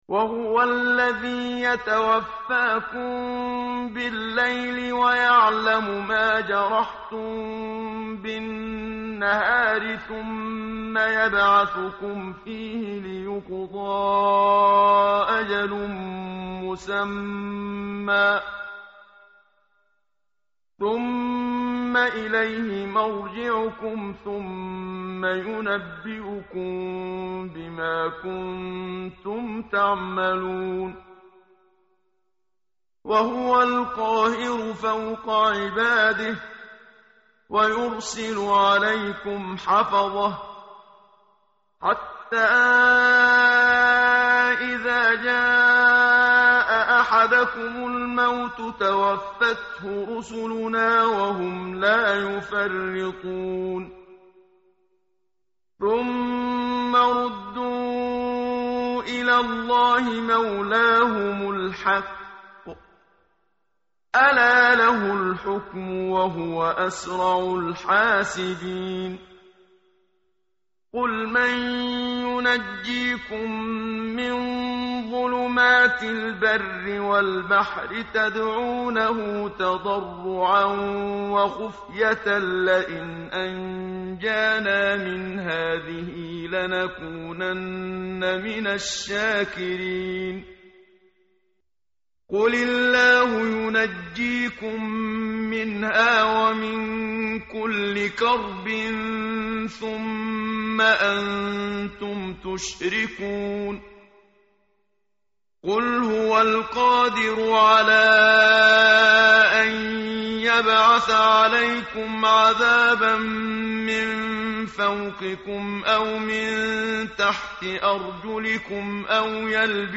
tartil_menshavi_page_135.mp3